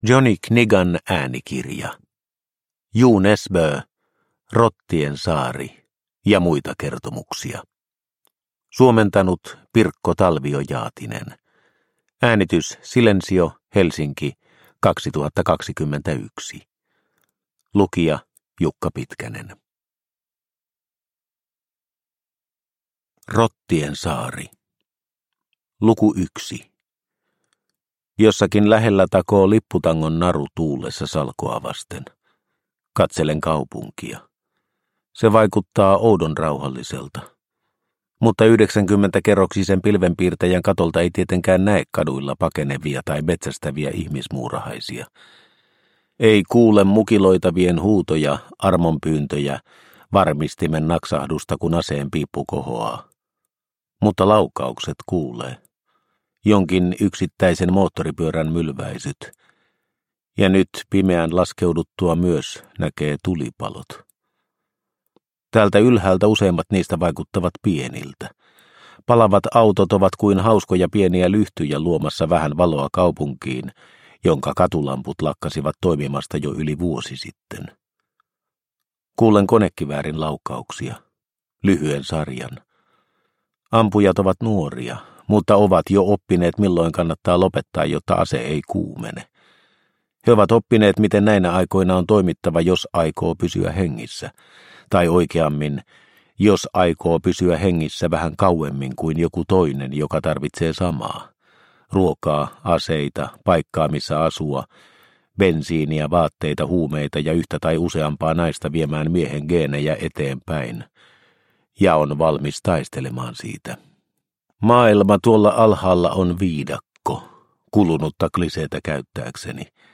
Rottien saari – Ljudbok – Laddas ner